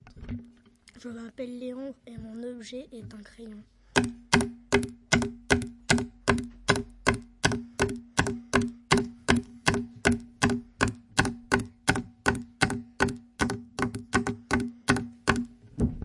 记事本
描述：笔写在记事本上。包括翻页和下笔。